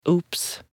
На этой странице собраны звуки «упс» — весёлые и искренние реакции на мелкие промахи.
Звук молодого мужчины при казусе или ошибке